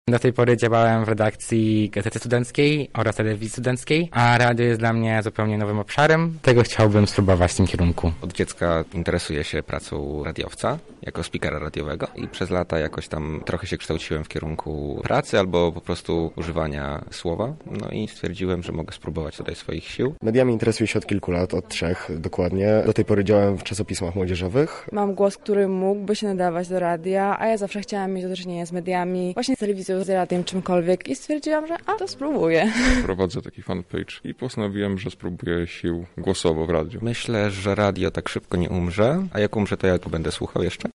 Z uczestnikami naboru rozmawiała nasza reporterka: